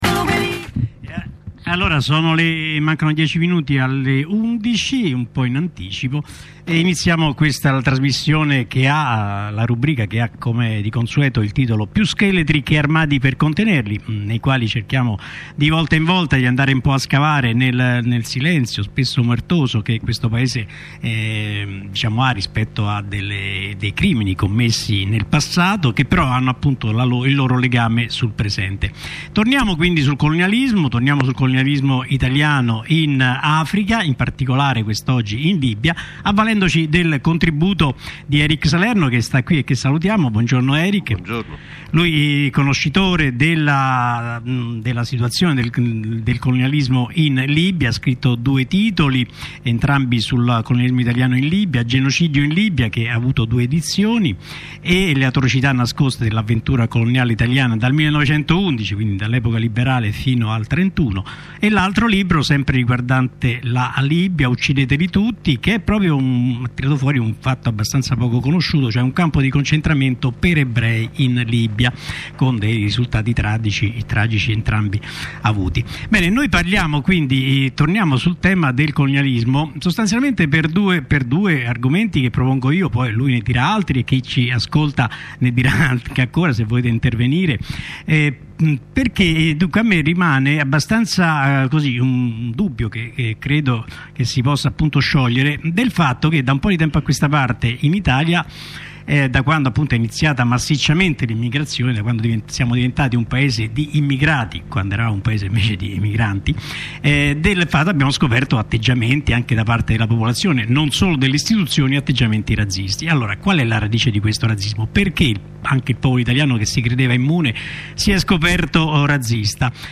Radio Onda Rossa presentazione del libro e intervista in diretta